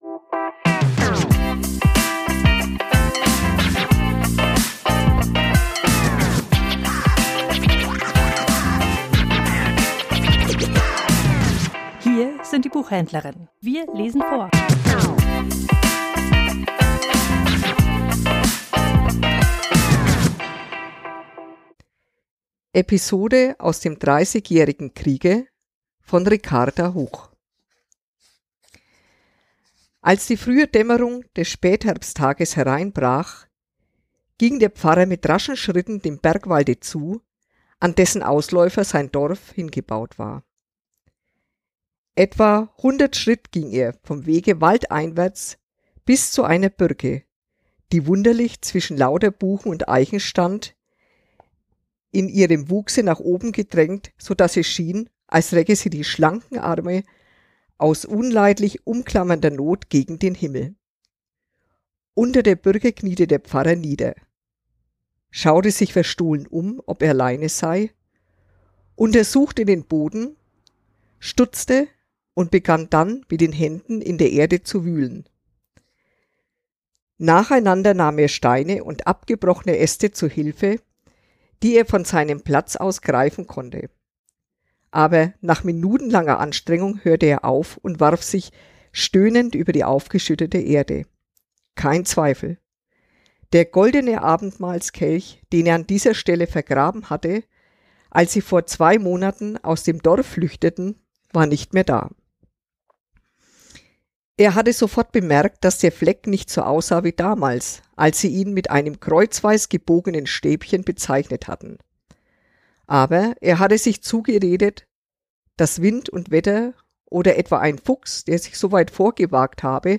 Vorgelesen: Episode aus dem 30jährigen Krieg ~ Die Buchhändlerinnen Podcast